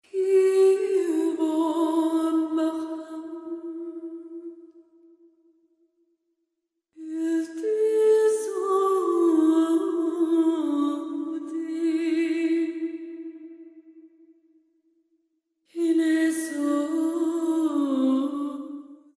File Type : Bollywood ringtones